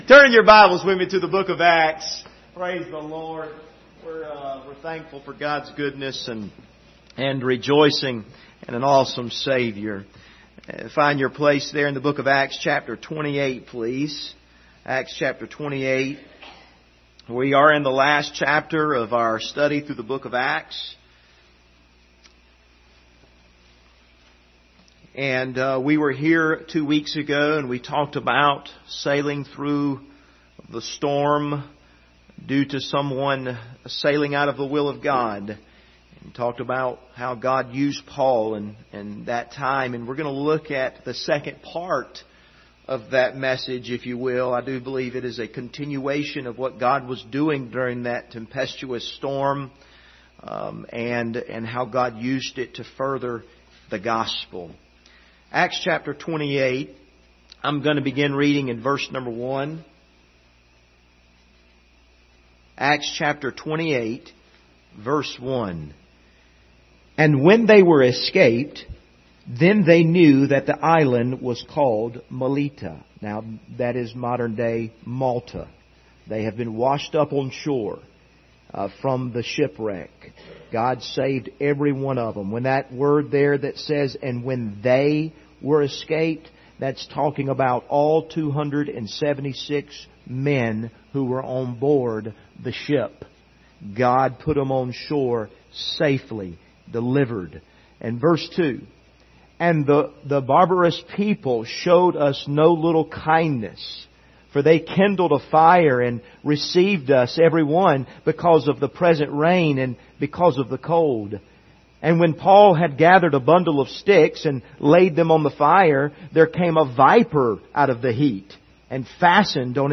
Service Type: Sunday Morning Topics: marriage , Parenting , resisting the devil